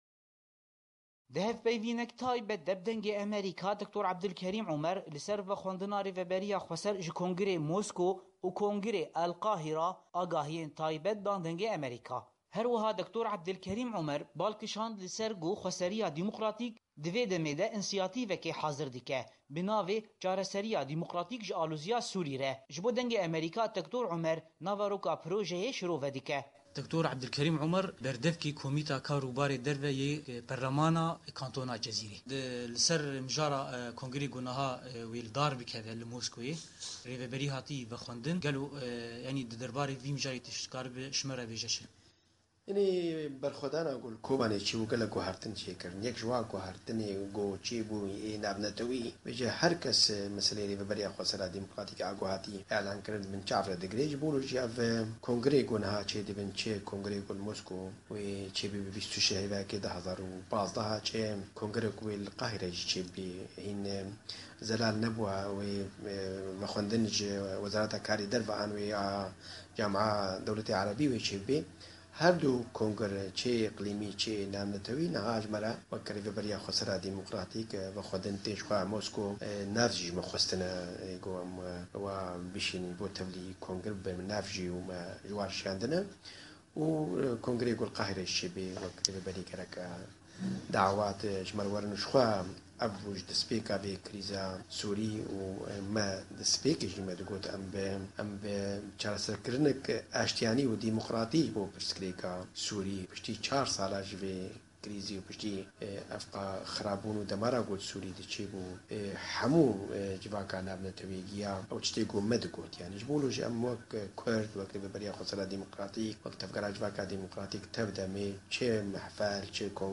Hevpeyvînek bi Dr. Ebdulkerîm Omer re